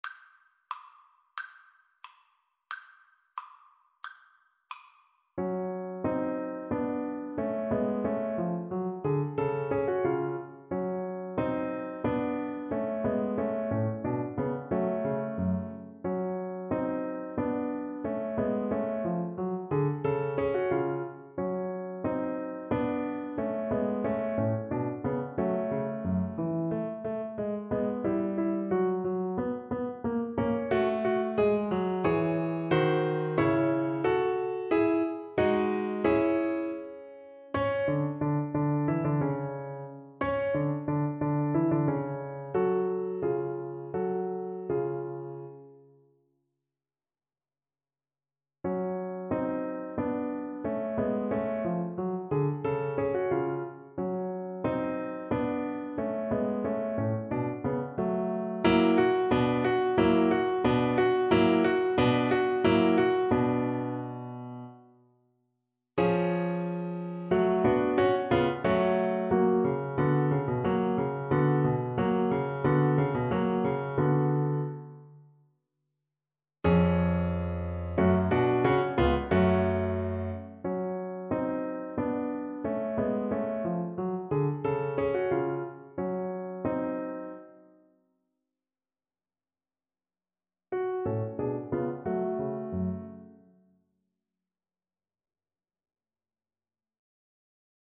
Violin
2/4 (View more 2/4 Music)
B4-Ab6
F# minor (Sounding Pitch) (View more F# minor Music for Violin )
Allegretto = 90
Classical (View more Classical Violin Music)